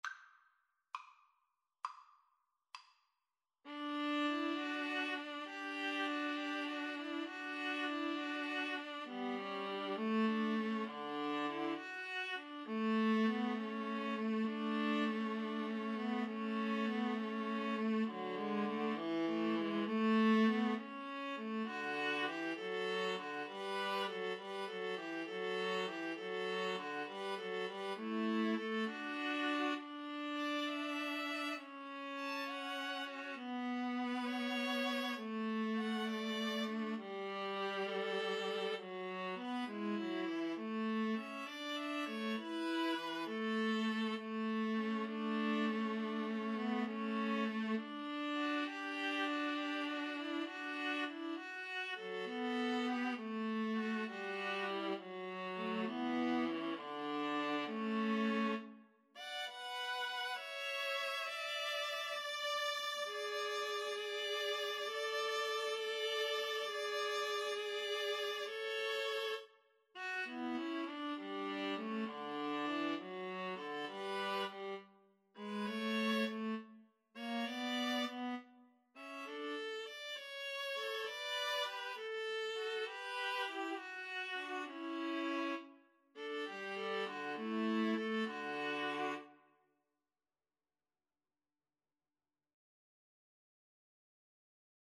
Free Sheet music for Viola Trio
D major (Sounding Pitch) (View more D major Music for Viola Trio )
Largo
Viola Trio  (View more Intermediate Viola Trio Music)
Classical (View more Classical Viola Trio Music)